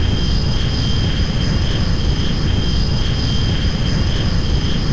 afterburner.mp3